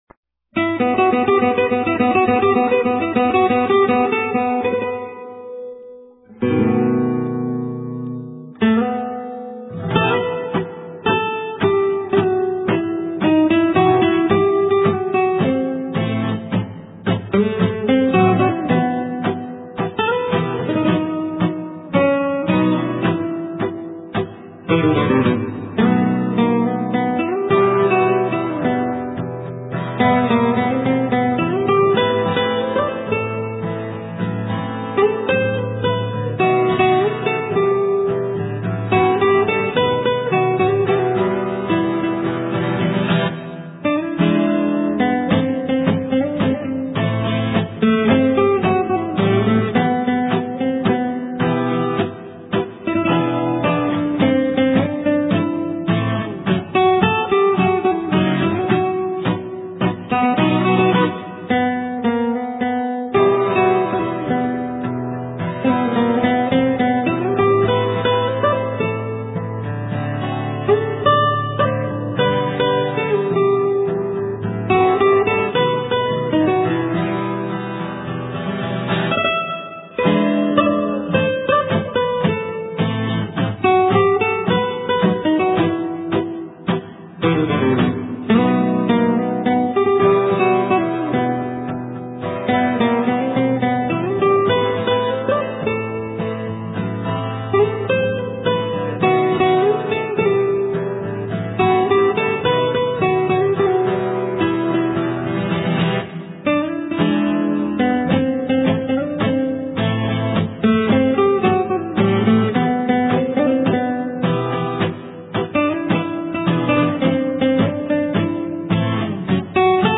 * Thể loại: Ngoại Quốc